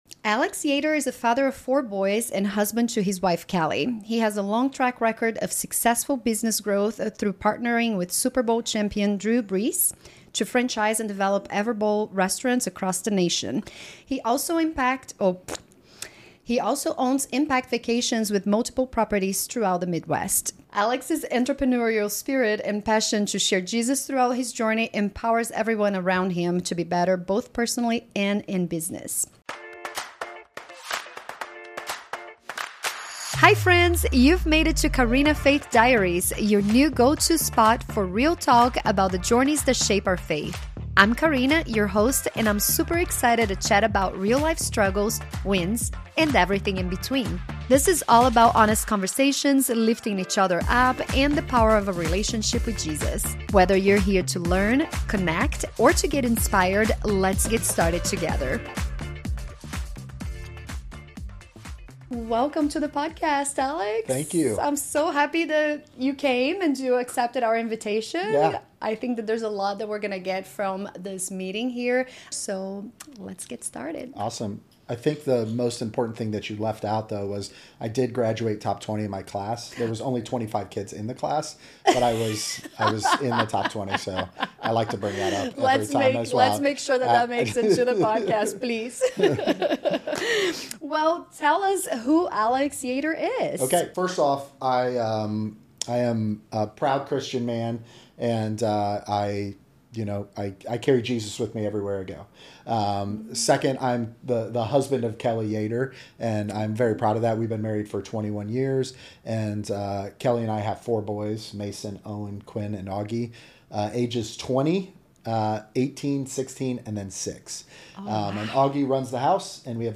Through open conversations